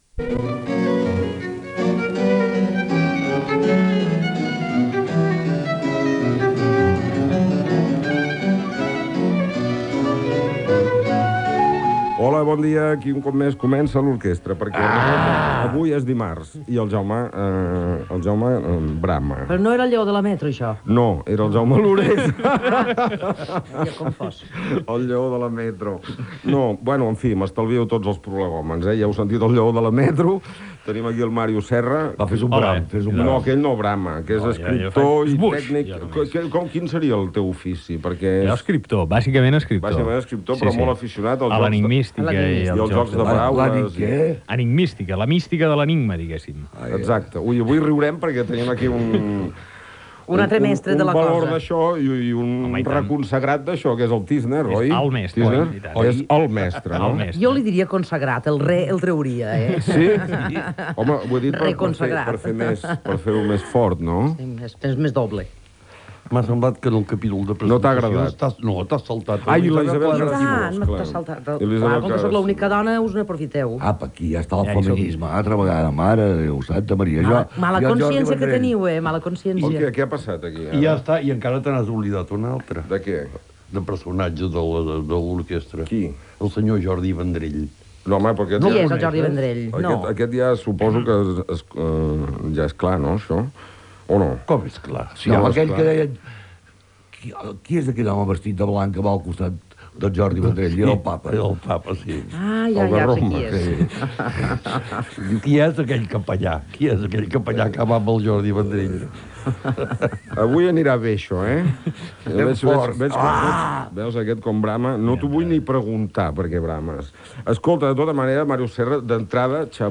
5da3794b77c8d542307af4fb07cd81224b6b4dfc.mp3 Títol Catalunya Ràdio Emissora Catalunya Ràdio Cadena Catalunya Ràdio Titularitat Pública nacional Nom programa L'orquestra Descripció Sintonia, presentació i tertúlia amb els escriptors Màrius Serra, Isabel Clara Simó, i Avel·lí Artís Gener "Tísner" sobre els jocs de paraules (anagrames, mots encreuats...)